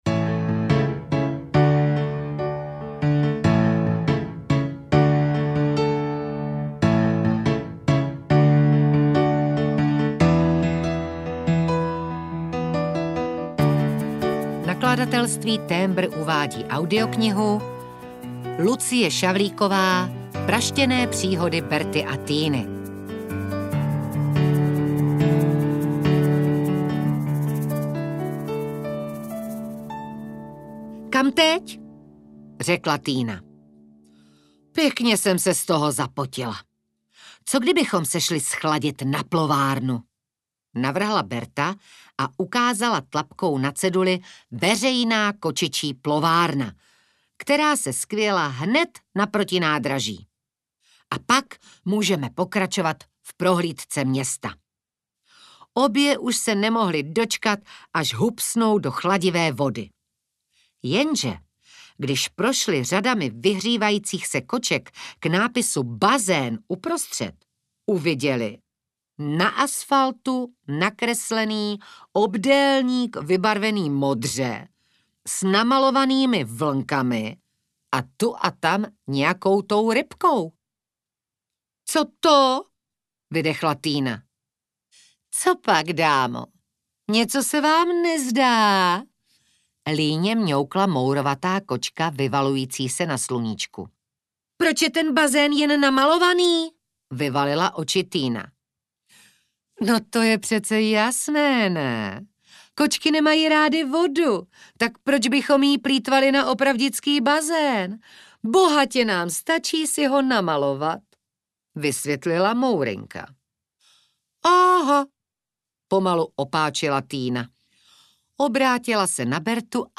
Ukázka z knihy
• InterpretSimona Babčáková
prastene-prihody-berty-a-tyny-audiokniha